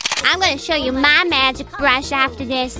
Worms speechbanks
CollectArm.wav